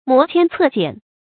磨鉛策蹇 注音： ㄇㄛˊ ㄑㄧㄢ ㄘㄜˋ ㄐㄧㄢˇ 讀音讀法： 意思解釋： 磨鈍刀，鞭蹇驢。